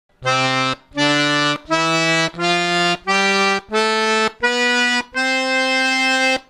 Lesson #6 - Fundamental major scale
The actual notes we played were:
C  D  E  F  G  A  B  C
scale.wma